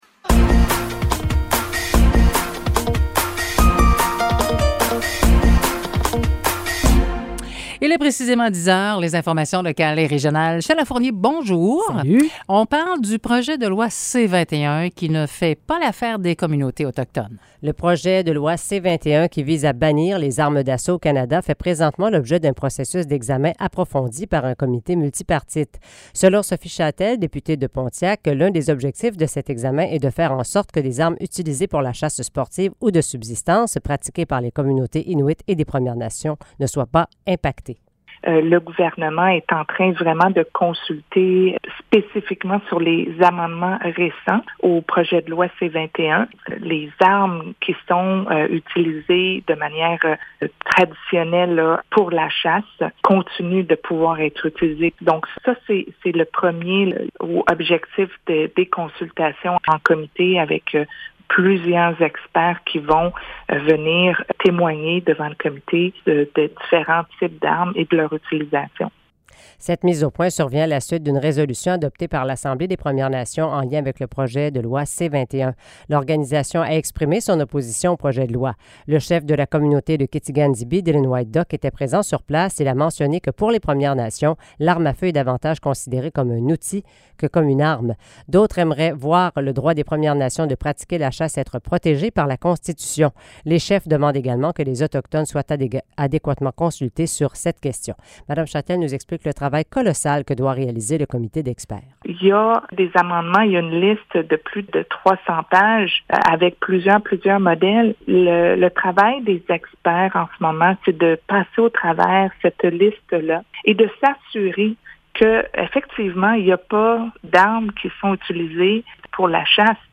Nouvelles locales - 14 décembre 2022 - 10 h